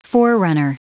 Prononciation du mot forerunner en anglais (fichier audio)
Prononciation du mot : forerunner